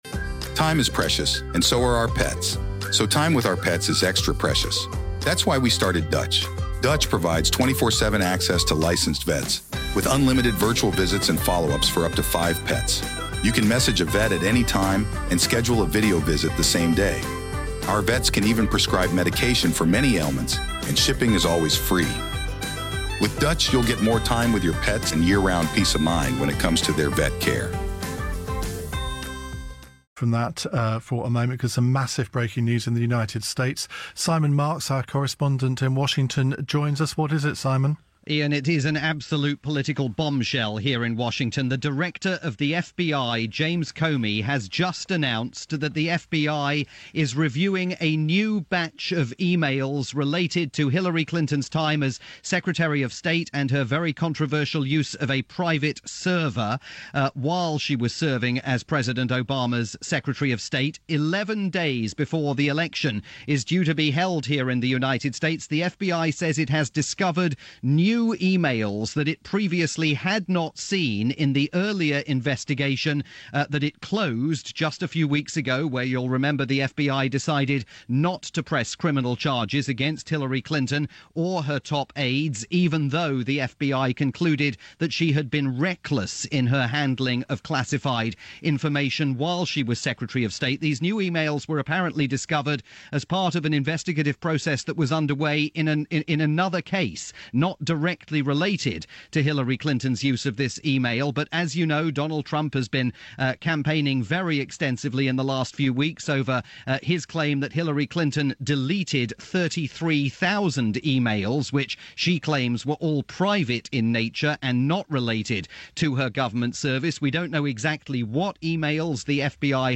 breaking news report from Iain Dale at Drive on LBC in the UK.